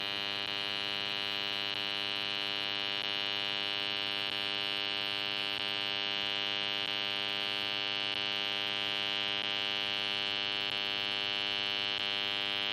AUDIOBOX USB Hummmmmm